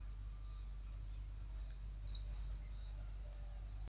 Suiriri-cavaleiro (Machetornis rixosa)
Nome em Inglês: Cattle Tyrant
Localidade ou área protegida: Concepción del Yaguareté Corá
Condição: Selvagem
Certeza: Observado, Gravado Vocal